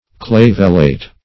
clavellate - definition of clavellate - synonyms, pronunciation, spelling from Free Dictionary
Clavellate \Clav"el*late\ (kl[a^]v"[e^]l*l[asl]t), a.